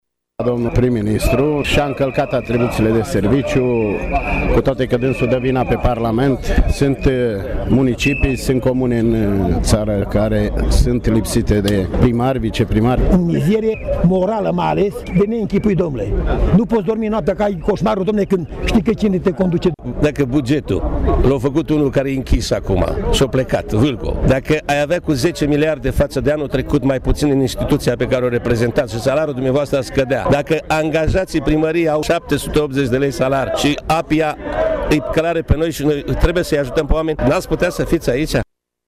Aproximativ 150 de oameni s-au adunat astăzi în fața Prefecturii Mureș, pentru a protesta împotriva Guvernului și a susține moțiunea de cenzură a PNL.